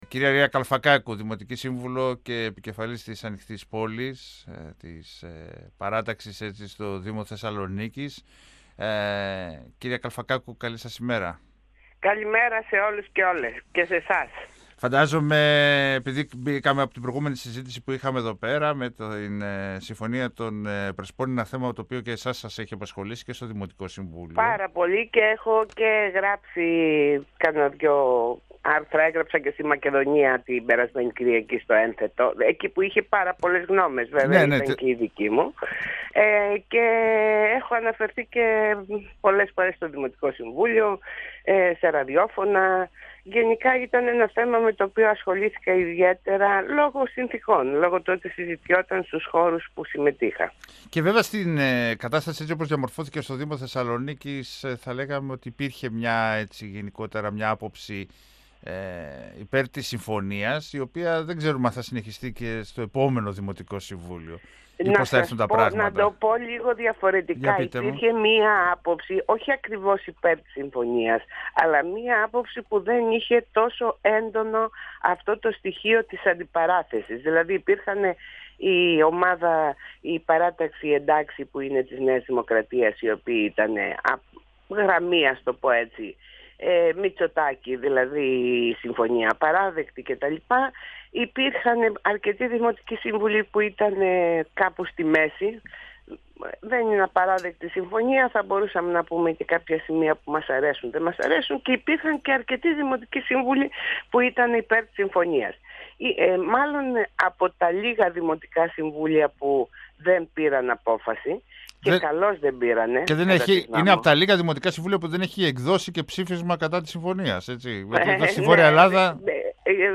Υπέρ της υποψηφιότητας του Σιμόν Μπενσανσόν για τη θέση του προέδρου του δημοτικού συμβουλίου του Δήμου Θεσσαλονίκης, και μάλιστα με συμβολικό χαρακτήρα, τάχθηκε μιλώντας στον 102 φμ η δημοτική σύμβουλος Ρία Καλφακάκου. Αναφερόμενη στην υποψηφιότητα της κ. Νοτοπούλου τόνισε ότι μπορεί μέσα και από ένα διευρυμένο ψηφοδέλτιο να αποτελέσει τη συνέχεια της πρωτοβουλίας.
Αναφερόμενη στην υποψηφιότητα της κ. Νοτοπούλου τόνισε ότι μπορεί μέσα και από ένα διευρυμένο ψηφοδέλτιο να αποτελέσει τη συνέχεια της πρωτοβουλίας. 102FM Συνεντεύξεις ΕΡΤ3